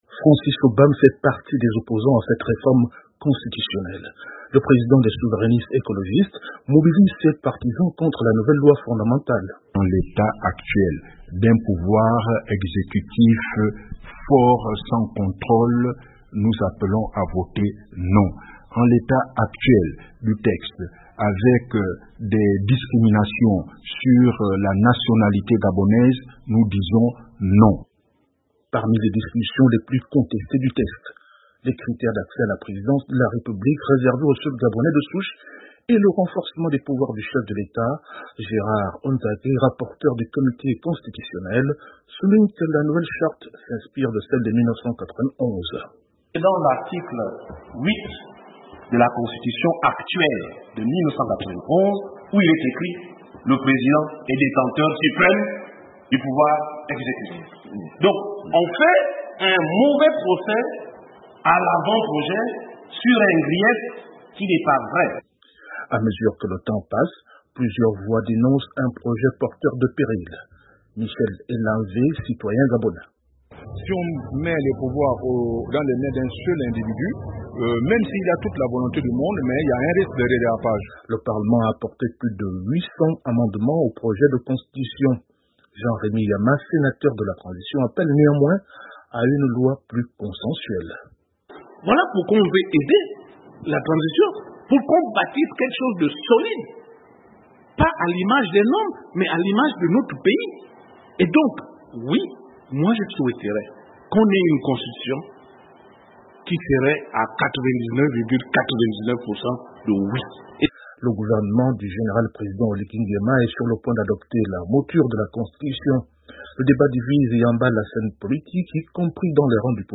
Le Gabon lance une caravane nationale d’information et de sensibilisation sur le référendum constitutionnel. Des débats autour de certaines dispositions du texte s’intensifient sur la scène publique. Le reportage de notre correspondant à Libreville